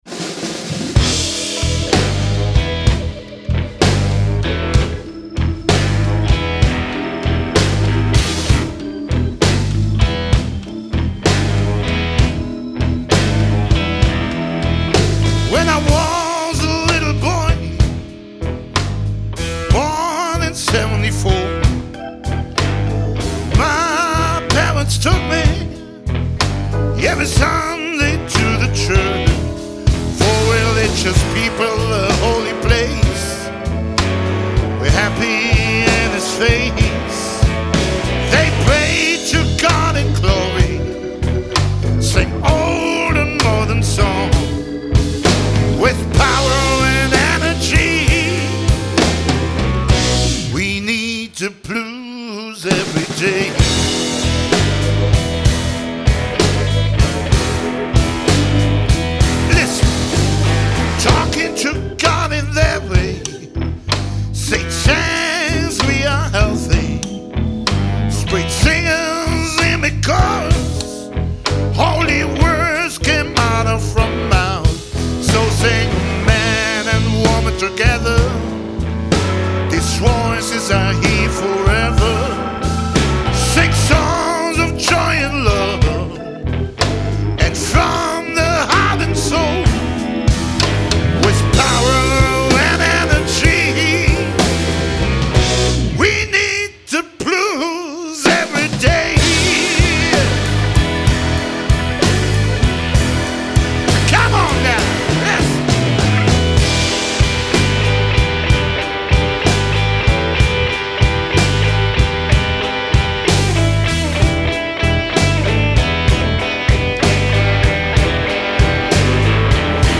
Aufnahme Live-Album in den Powerplay Studios Maur/ZH